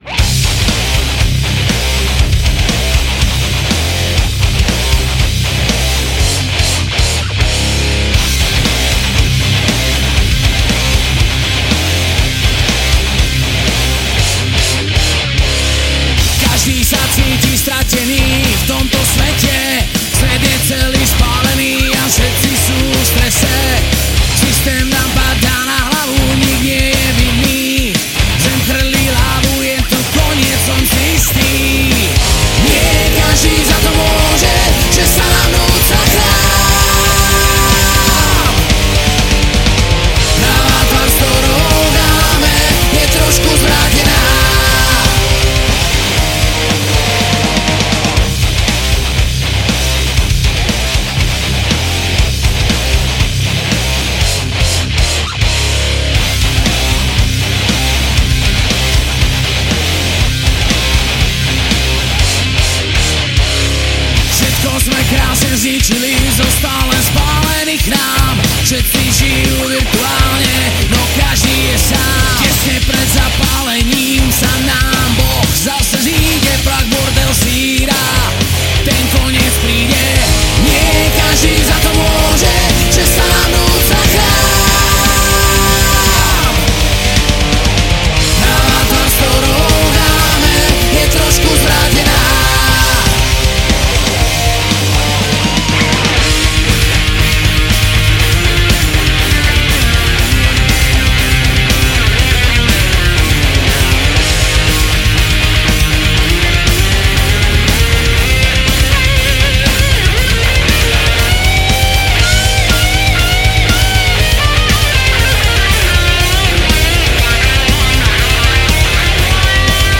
Žánr: Rock
Gitary, spev
Bicie
Spev
Klávesy